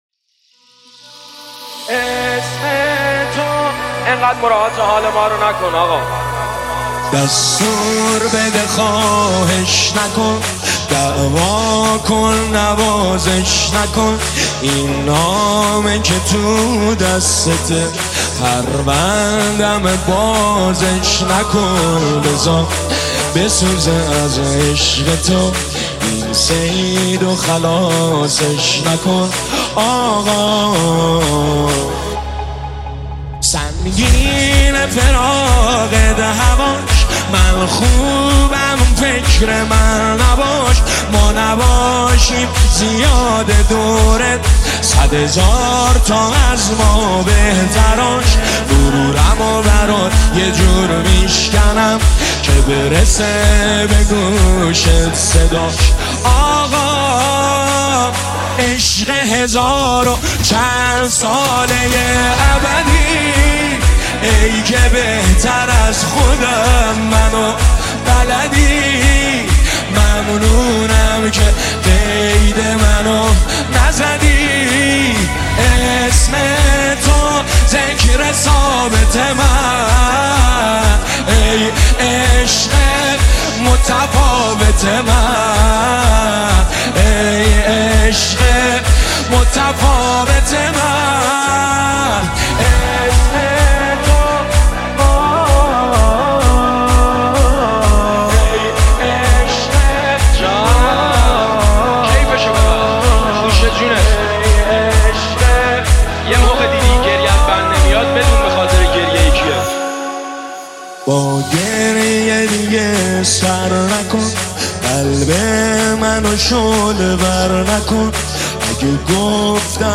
نماهنگ دلنشین مهدوی